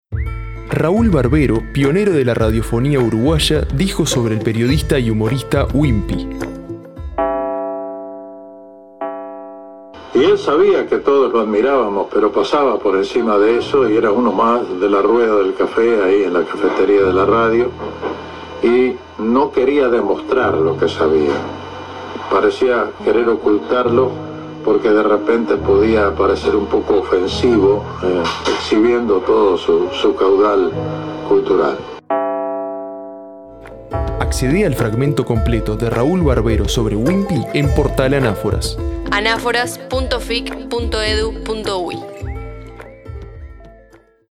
Esta serie recupera fragmentos de obras literarias, entrevistas, citas y conferencias; a través de textos y narraciones con las voces de poetas, periodistas y académicos que integran el repositorio.